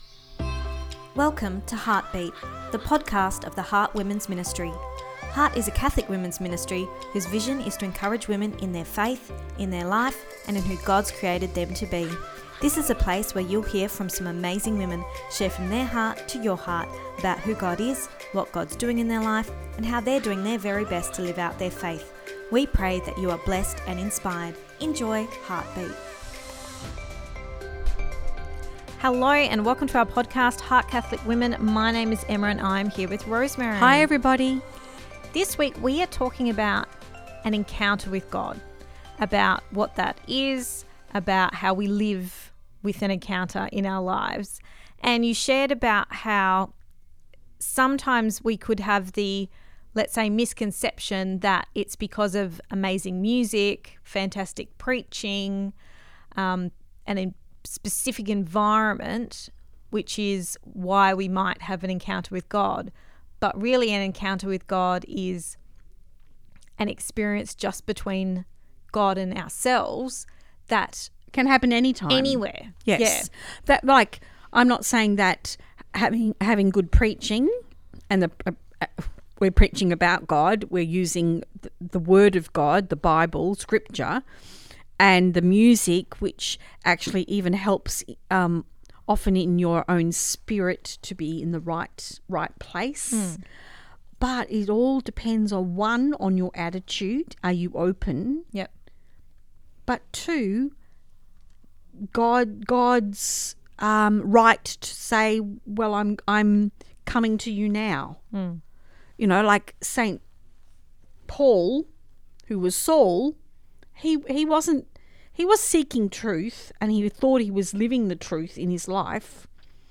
Ep257 Pt2 (Our Chat) – An Encounter with God